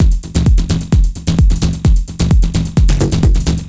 Swine2 130bpm.wav